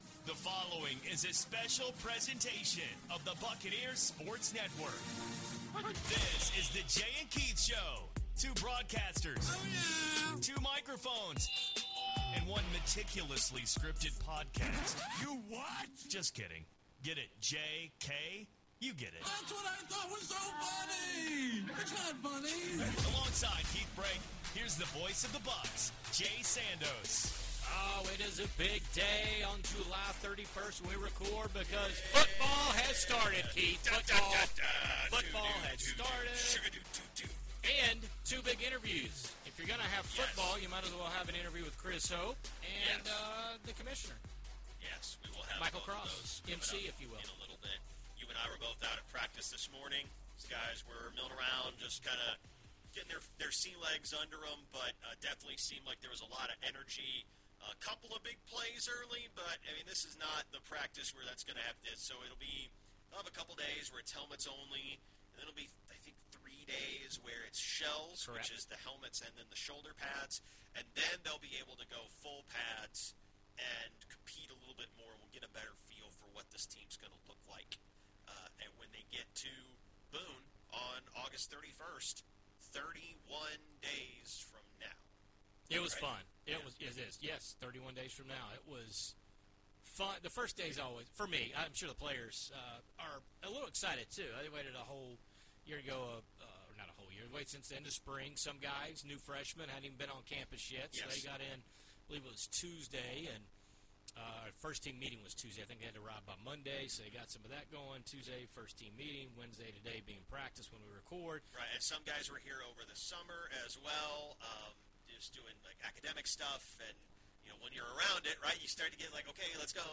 Studio segments recorded July 31st, 2024; interviews recorded July 23rd, 2024.